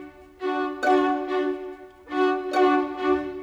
Modern 26 Strings 03.wav